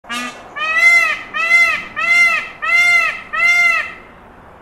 Звук раскатистого павлиньего крика